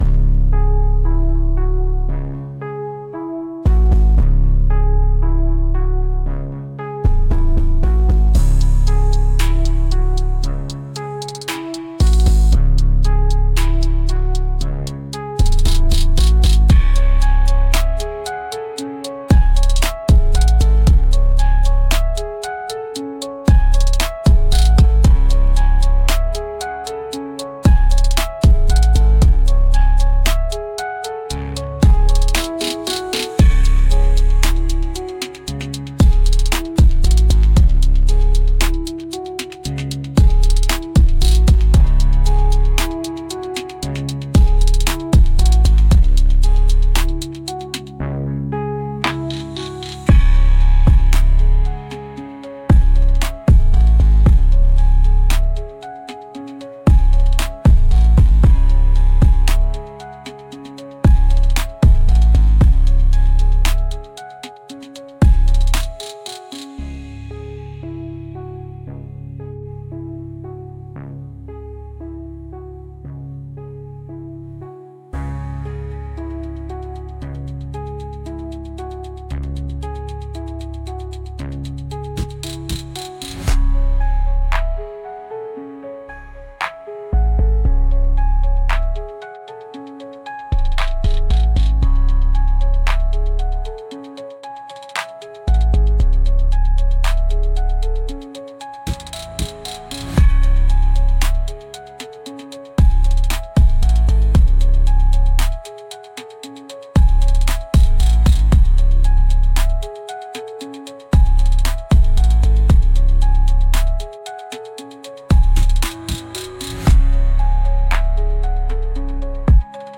Жанр: hip-hop, trap